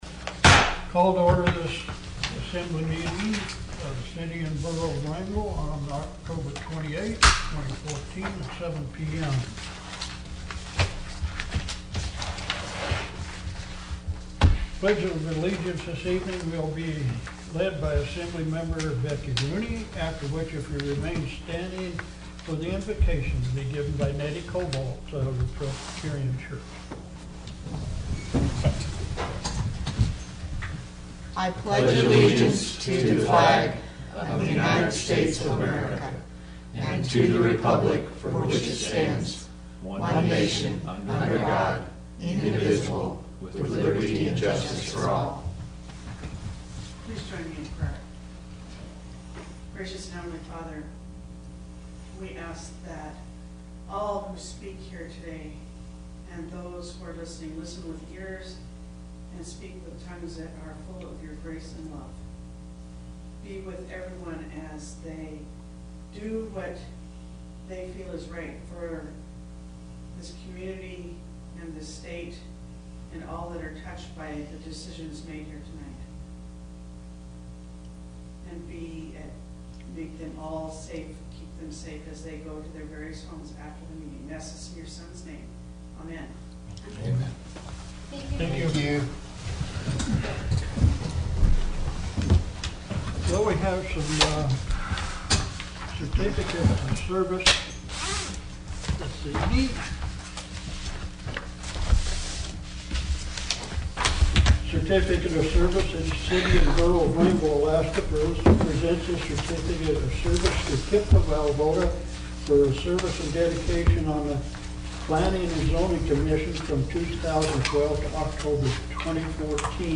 Wrangell's Borough Assembly met for a regular meeting Tuesday, Oct. 28 in the Assembly Chambers.
City and Borough of Wrangell Borough Assembly Meeting AGENDA October 28, 2014–7 p.m. Location: Assembly Chambers, City Hall